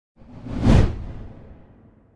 transition whoosh Meme Sound Effect
transition whoosh.mp3